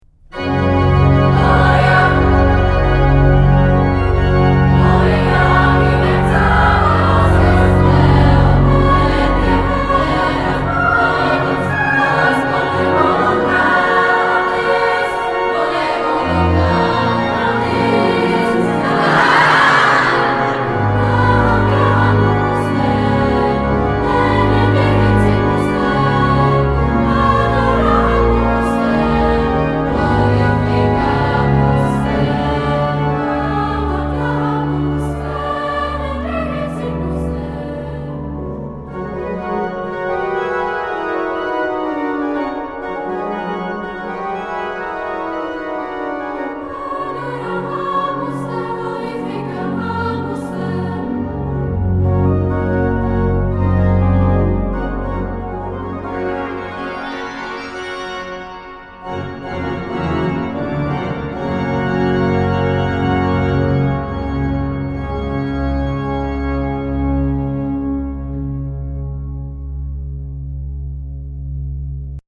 varhanní verze